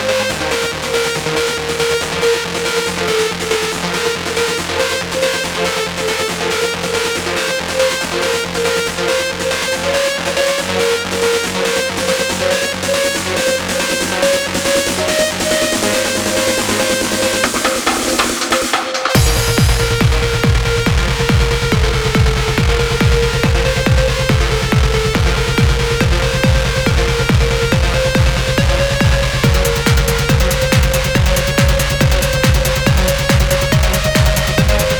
Жанр: Электроника
# Electronic